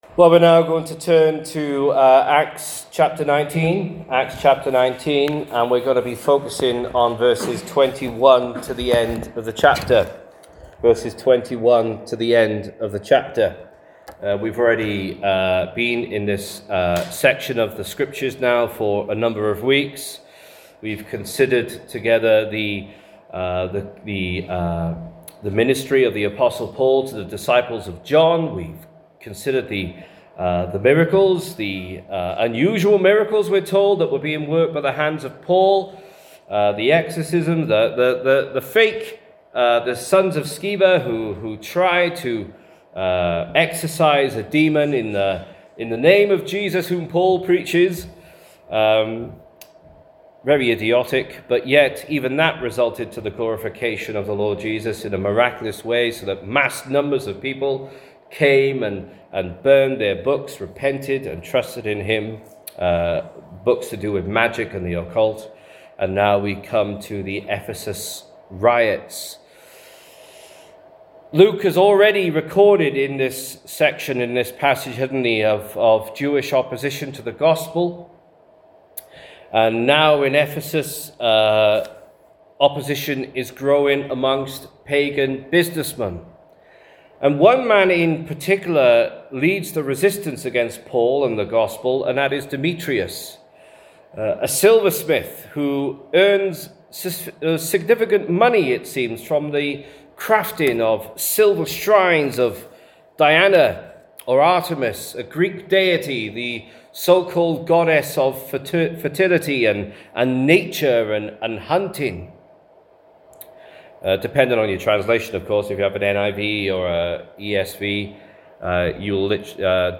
Sermon: The Ephesus Riots (Acts 19:19-41) – Union Croft Chapel
This sermon was preached on the morning of 25th January.